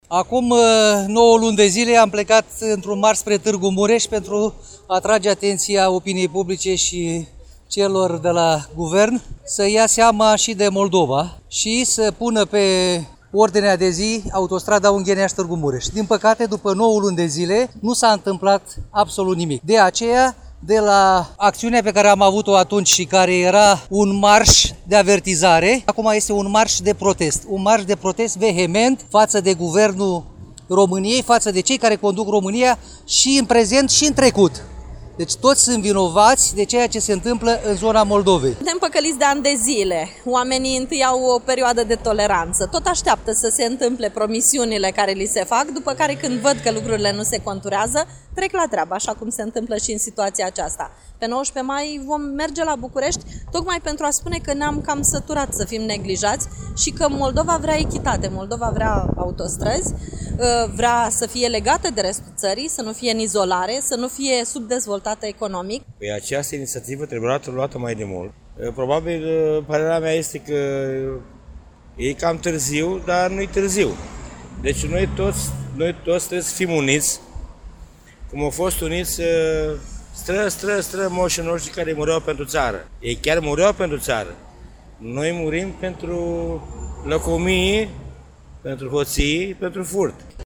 10-mai-vox-autostrada.mp3